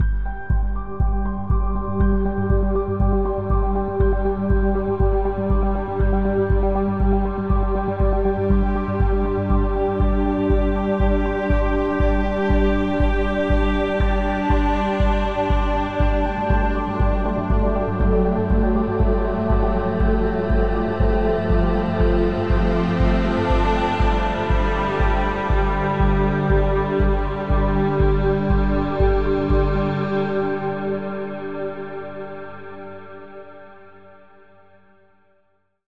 Added Ambient music pack.